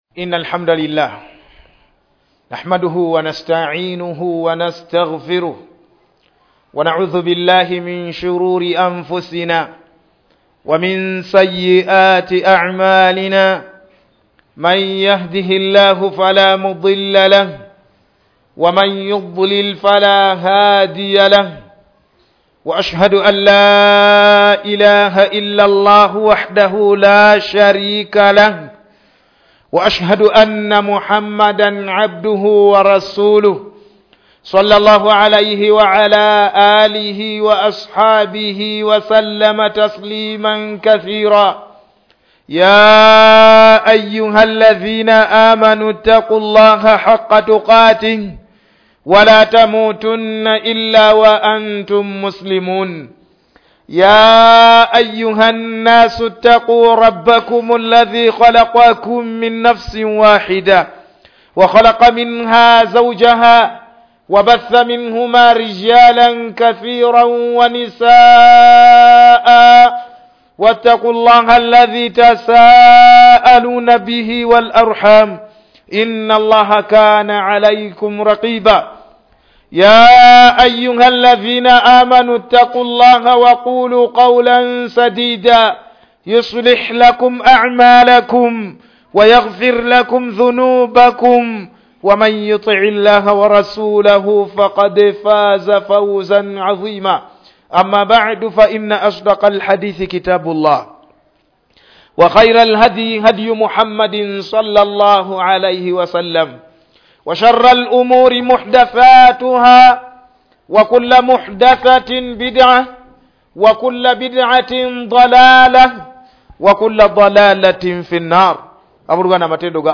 Serie : JUMMA KHUTUB